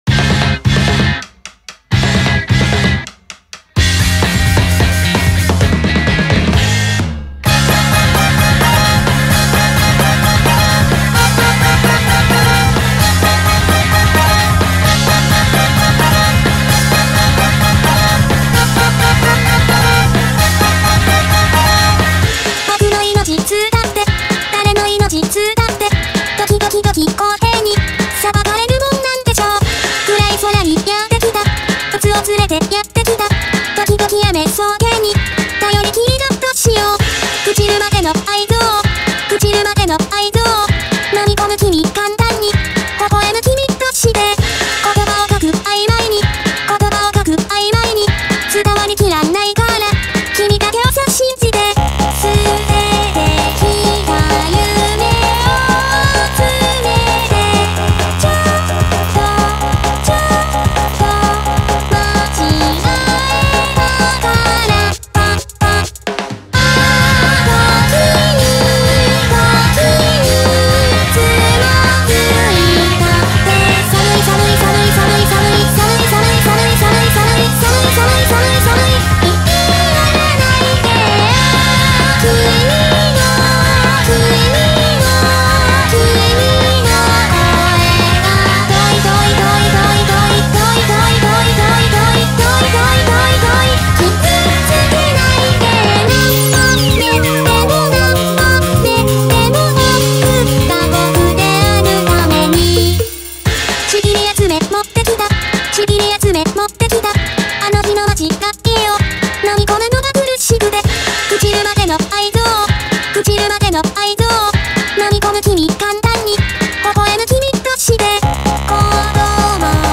وکالویید
فضای موسیقی الکترونیک، تند و احساسی است